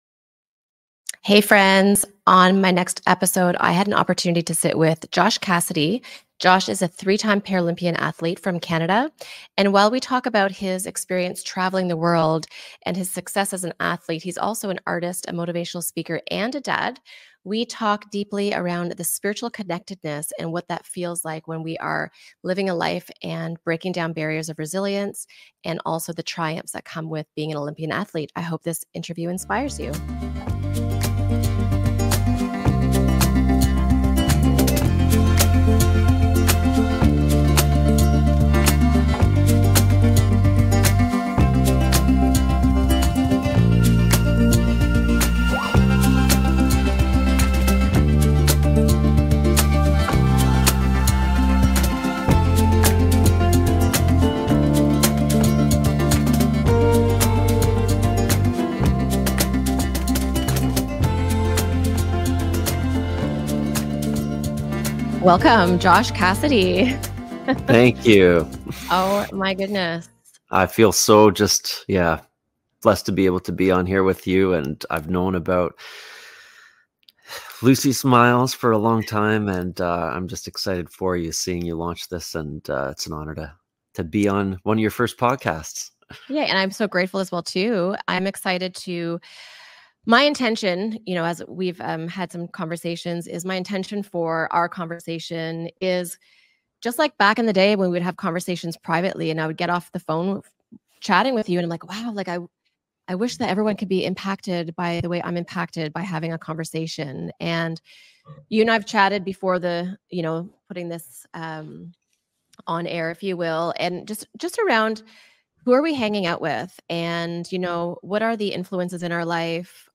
We get cozy on the couch in a transformative conversation with the remarkable Josh Cassidy, 3 X Paralympian and 31 x Canadian Champion.From conquering the Paralympic Games to inspiring audiences worldwide, Josh shares his profound insights on harnessing inner strength and spiritual connection to triumph in every facet of life.